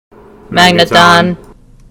Cries
MAGNETON.mp3